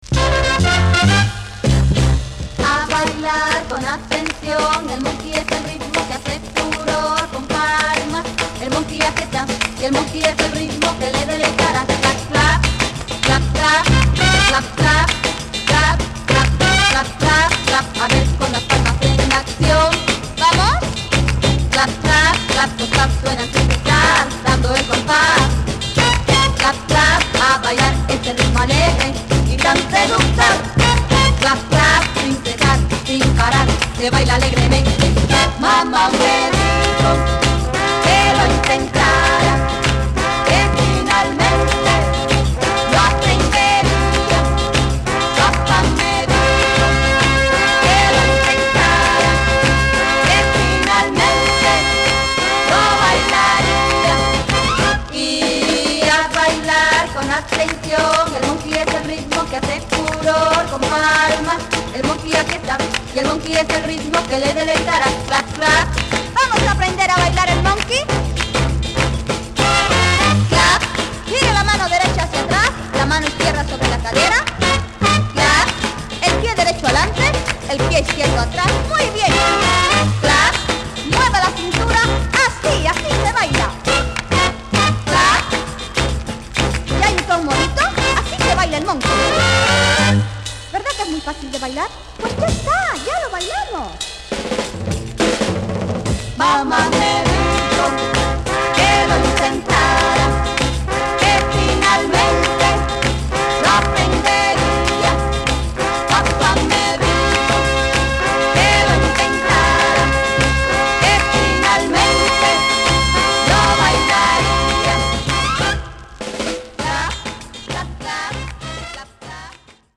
Latin spain